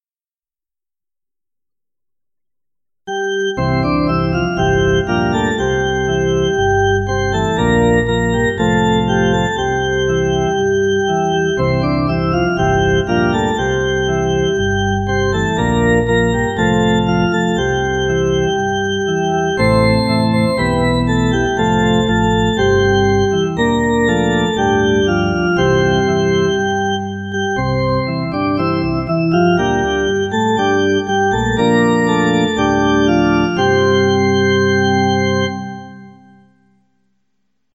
Psalm 95. Come Let Us Sing for Joy to the Lord (Doxology). A joyous doxology of praise and thanksgiving.